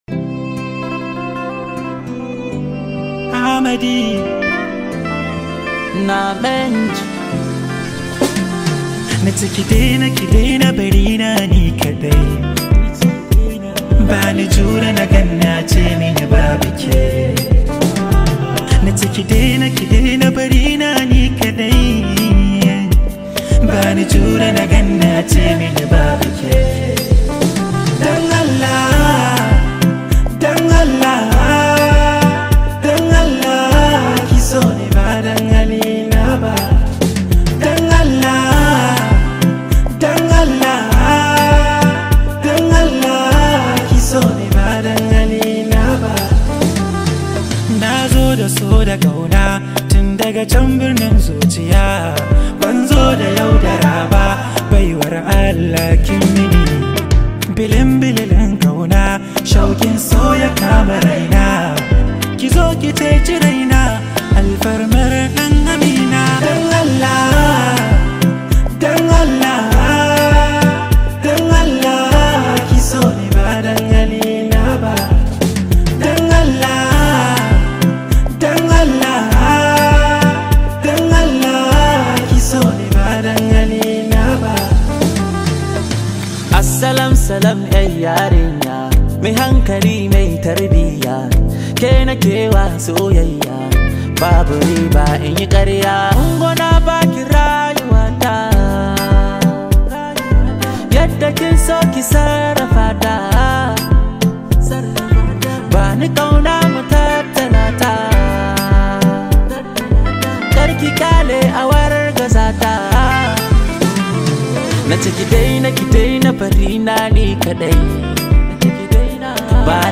Hausa Singer
as it comes with a lot of energy and positive Vibes